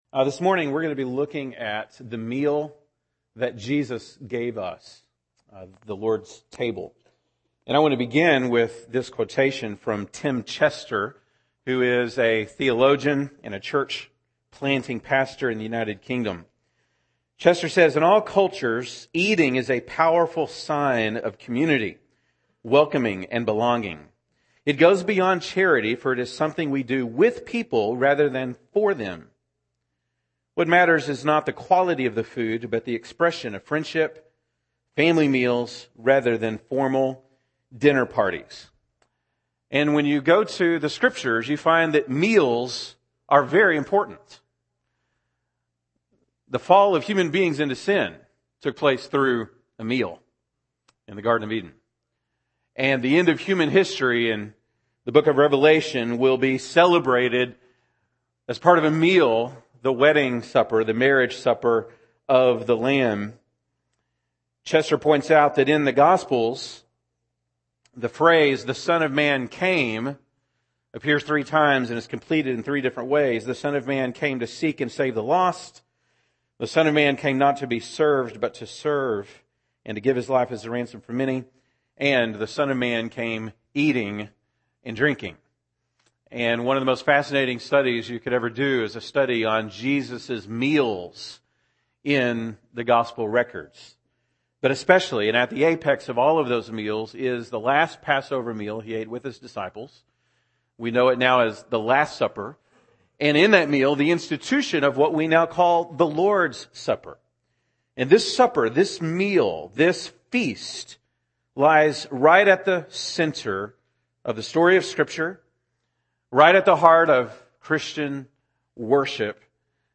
April 17, 2016 (Sunday Morning)